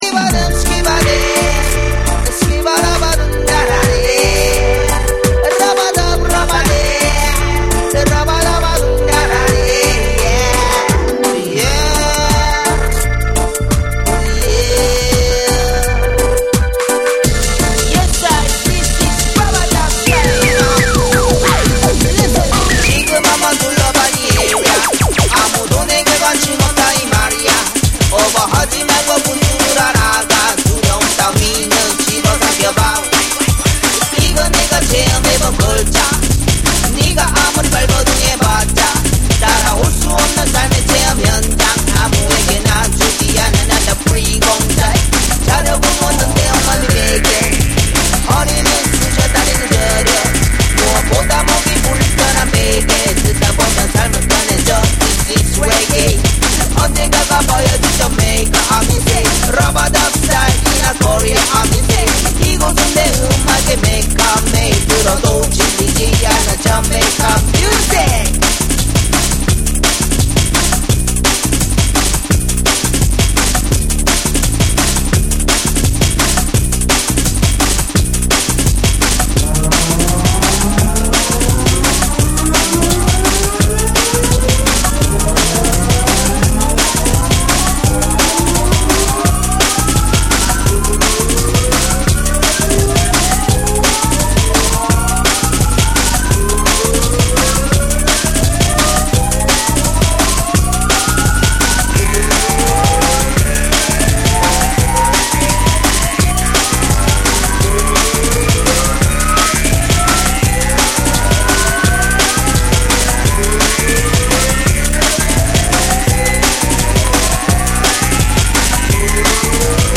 JUNGLE & DRUM'N BASS